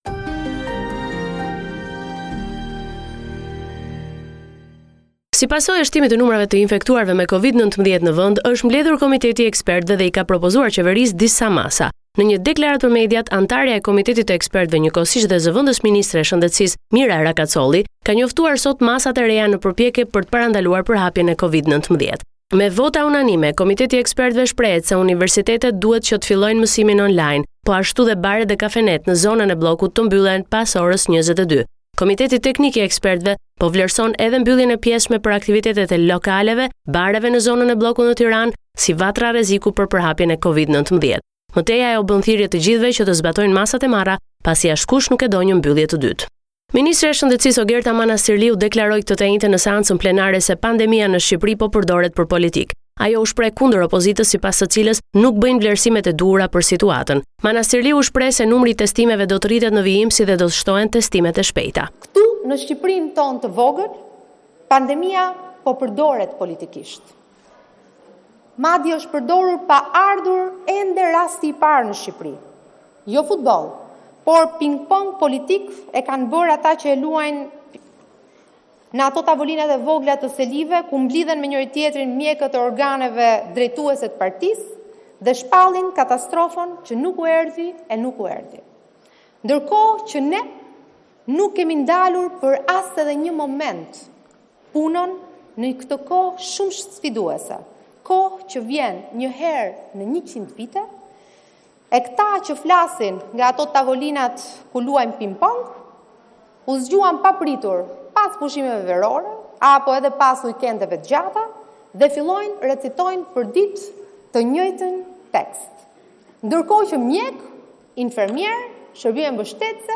This is a report summarising the latest developments in news and current affairs in Albania.